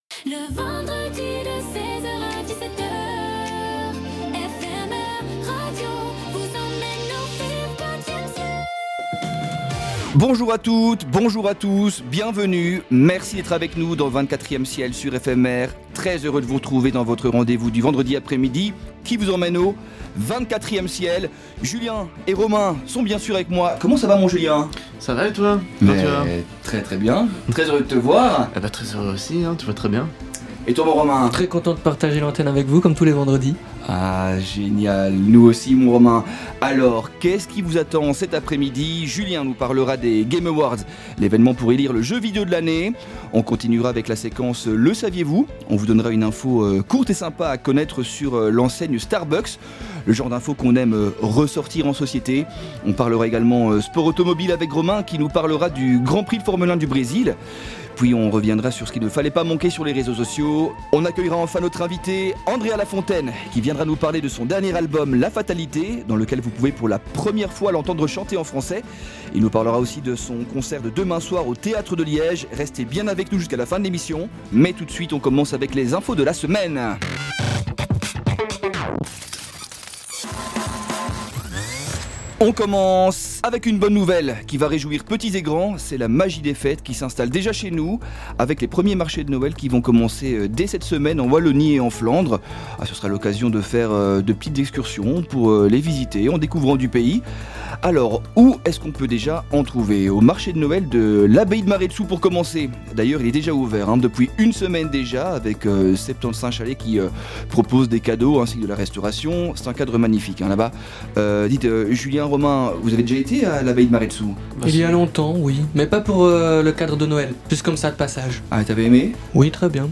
On vous promet un grand bol de bonne humeur avant d'entamer le week-end !